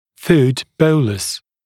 [fuːd ‘bəuləs][фу:д ‘боулэс]пищевой комок